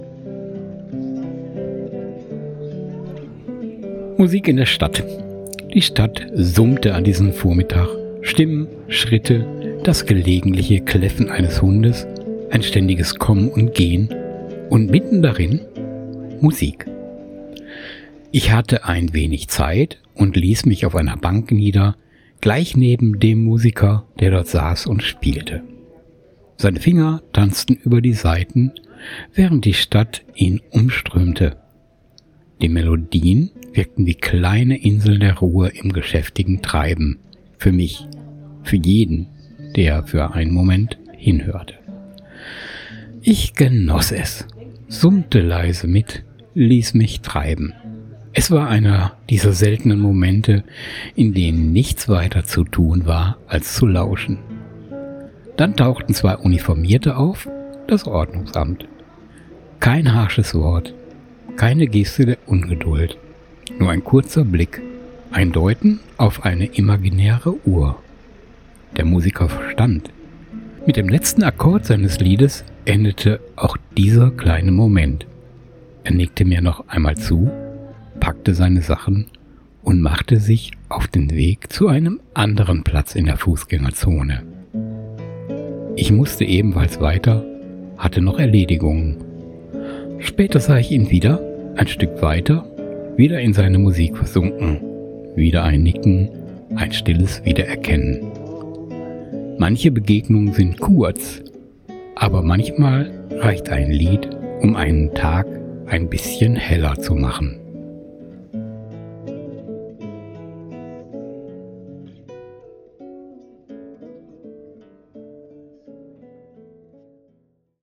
Sraßenmusiker gehören zum Bild der Stadt. hier ist ein Erlebnis…